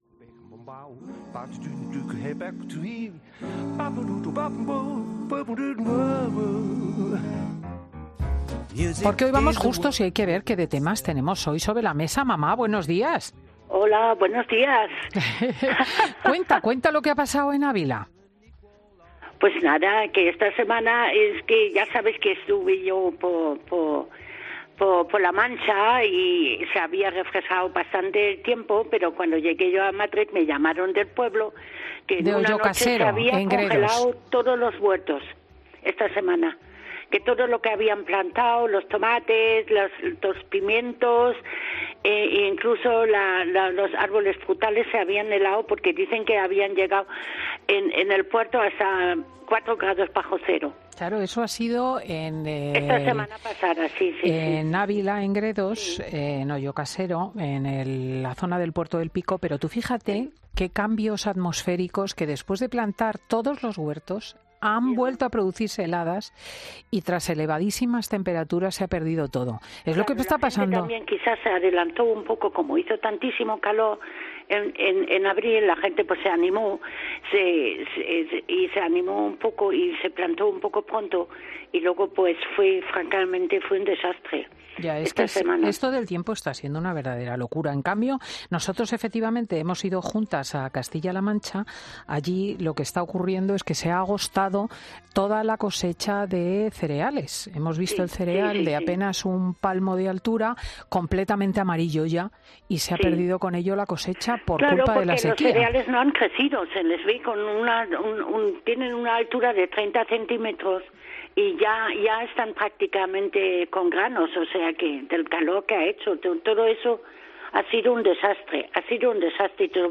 Conversaciones con mi madre